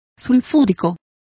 Complete with pronunciation of the translation of sulfuric.